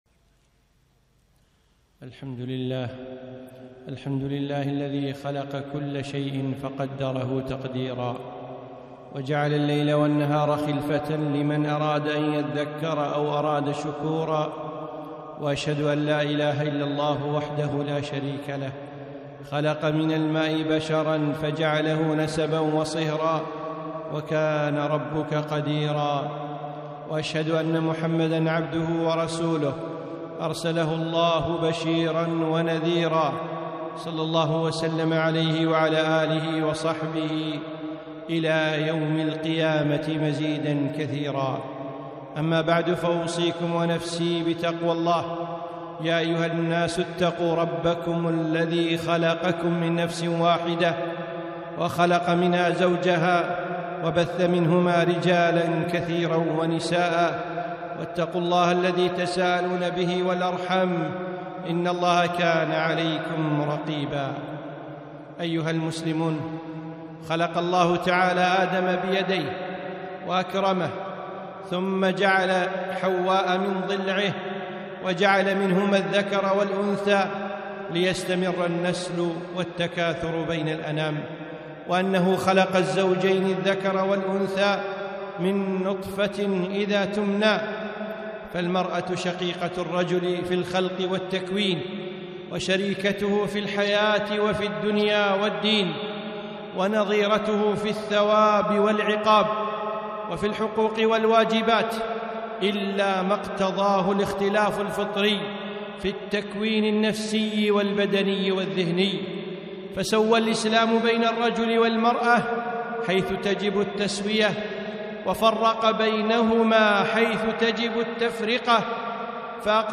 خطبة - واجبات المرأة المسلمة